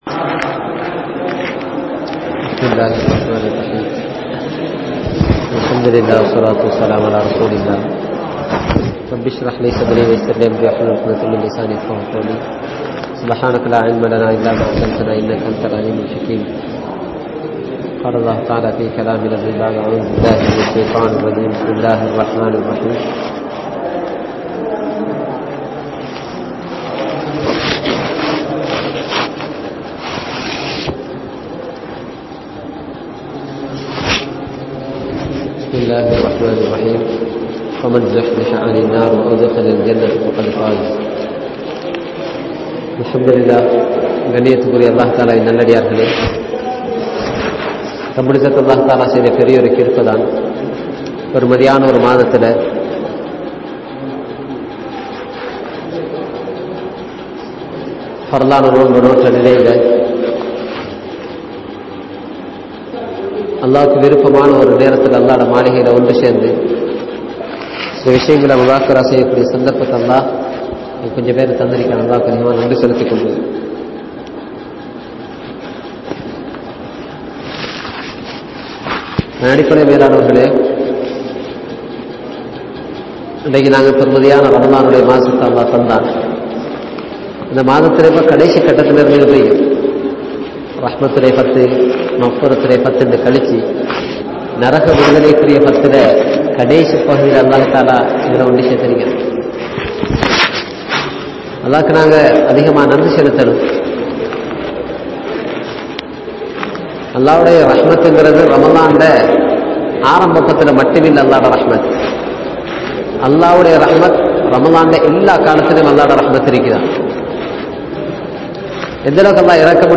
Maranaththai Maranthu Vidaatheerhal (மரணத்தை மறந்து விடாதீர்கள்) | Audio Bayans | All Ceylon Muslim Youth Community | Addalaichenai